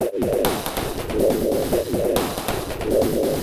E Kit 37.wav